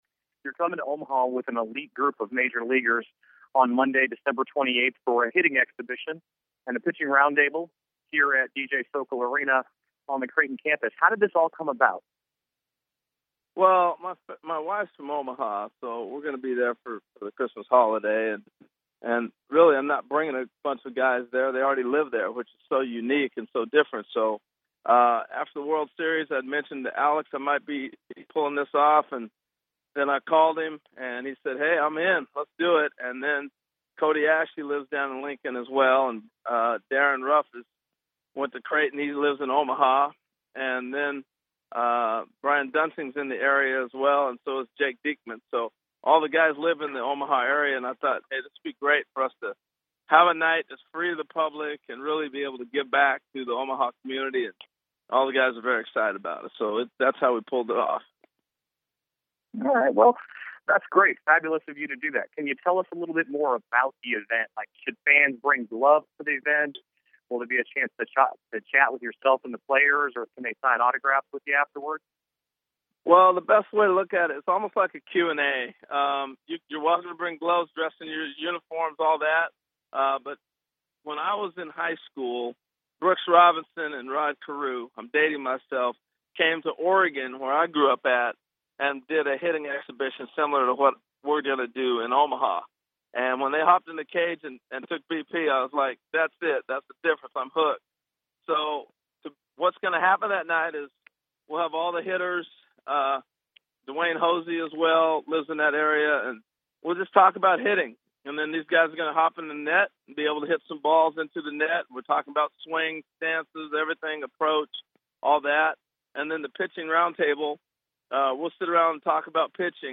As part of the preview media tour Reynolds took a few moments to chat with a Creighton baseball representative about the upcoming event.
Q&A with Harold Reynolds